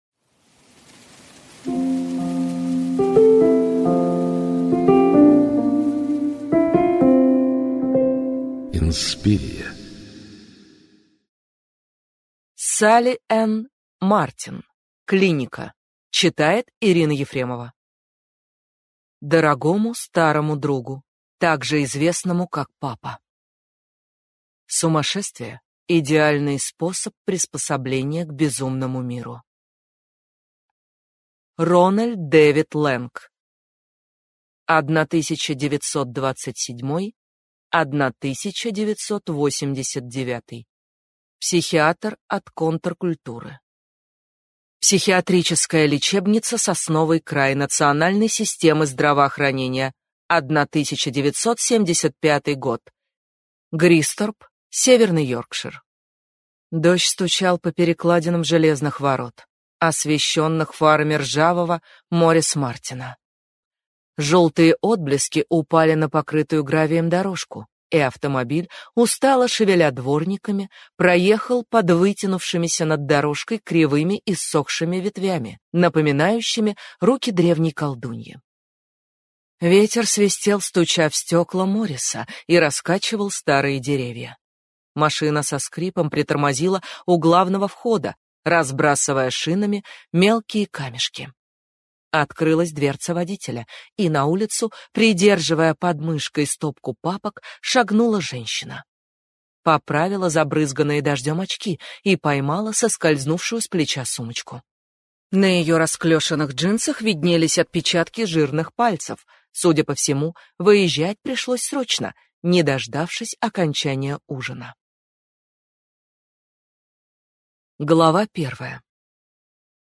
Аудиокнига Клиника | Библиотека аудиокниг